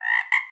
animalia_frog_2.ogg